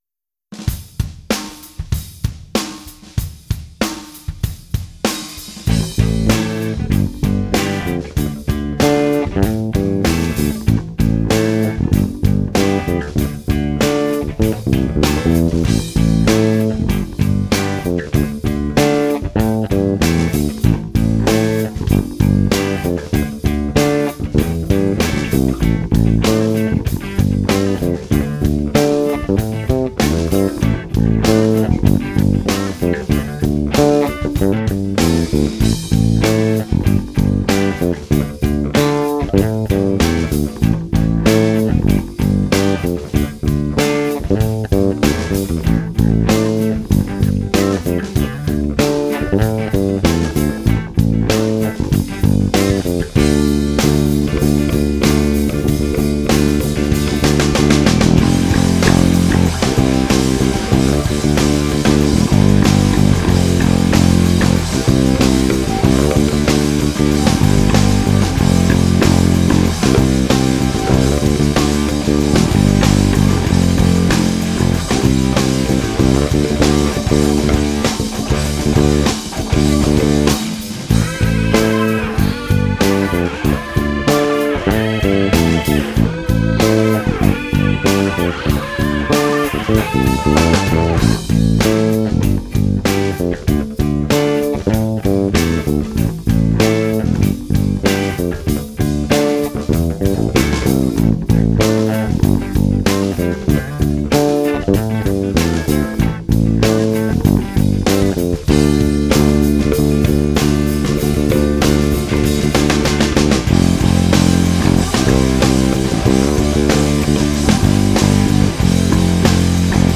instrumental
Cover / Bass only